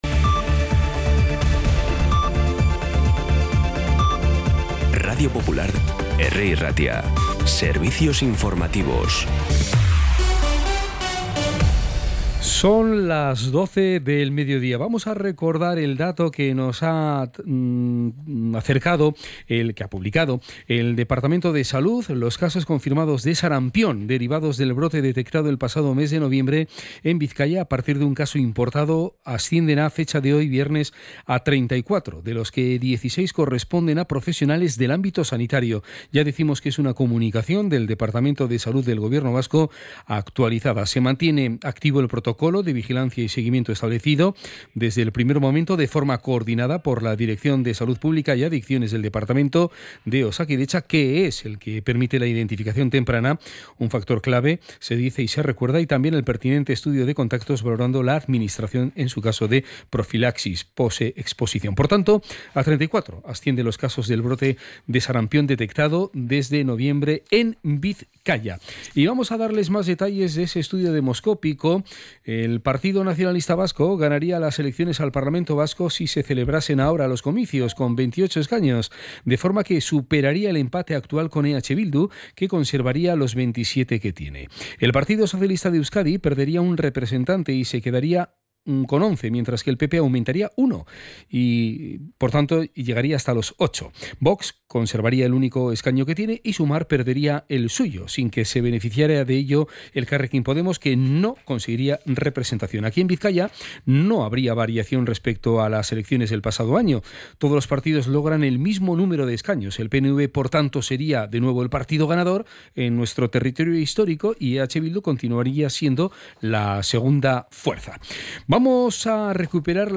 Las noticias de Bilbao y Bizkaia del 7 de febrero a las 12
Los titulares actualizados con las voces del día.